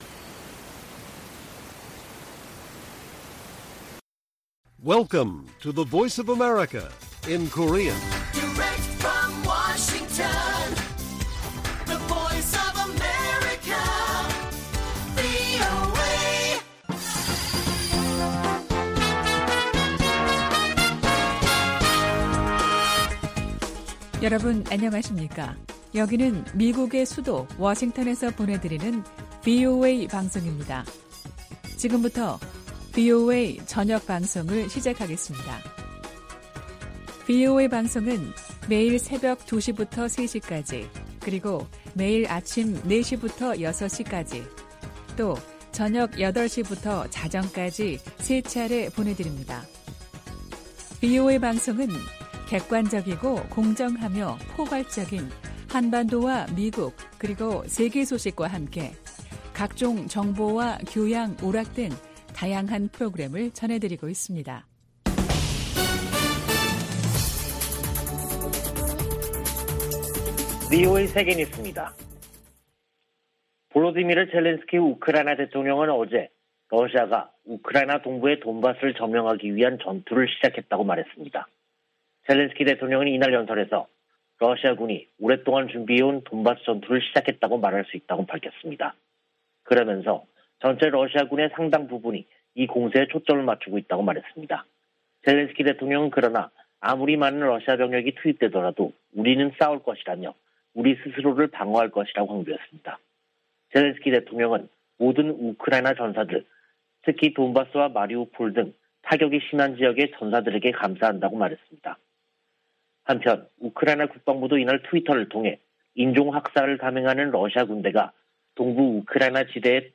VOA 한국어 간판 뉴스 프로그램 '뉴스 투데이', 2022년 4월 19일 1부 방송입니다. 미 국무부는 북한이 도발을 계속하면 상응 조치를 이어갈 것이라고 경고했습니다. 미 국방부는 북한이 신형 전술유도무기를 시험 발사했다고 밝힌 데 관한 정확한 평가를 위해 분석 중이라고 밝혔습니다. 유엔은 신형 전술유도무기를 시험했다는 북한의 주장에 대해 긴장을 고조시킬 뿐이라고 비판했습니다.